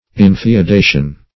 Infeodation \In`feo*da"tion\